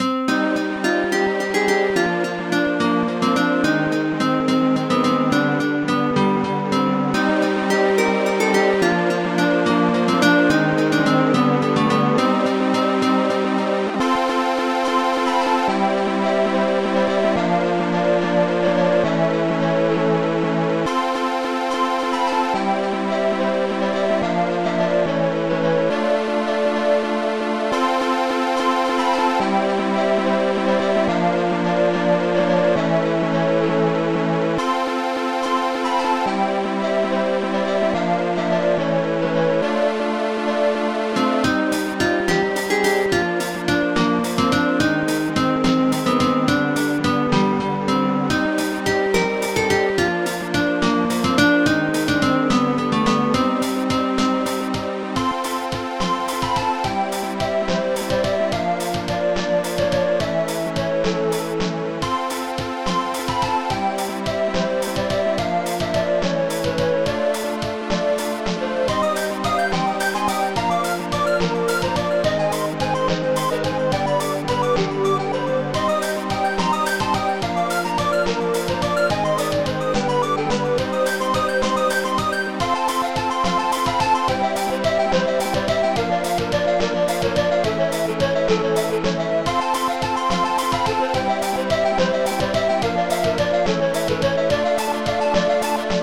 Protracker Module
Type Protracker and family
st-02:guitar1 st-02:cliop st-06:demonMIN st-06:demonMAJ st-09:snarerev1 st-09:kick1 st-09:hihatopen